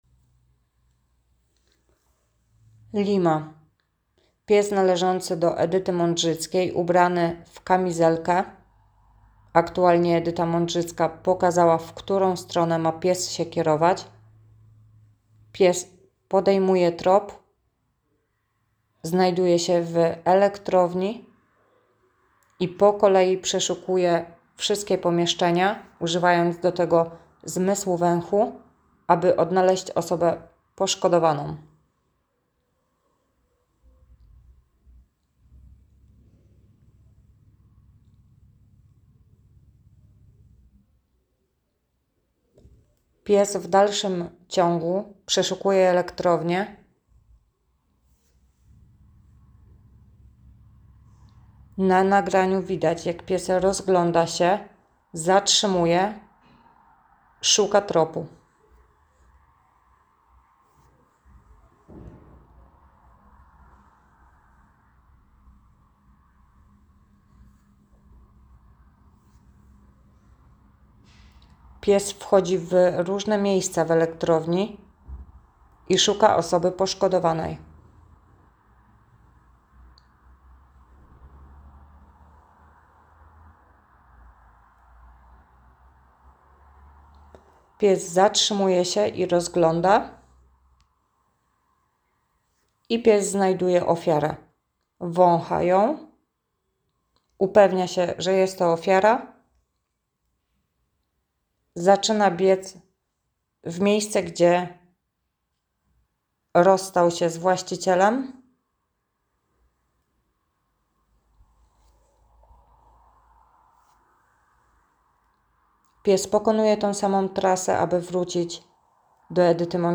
Nagranie audio Audiodykrypcja_Lima.m4a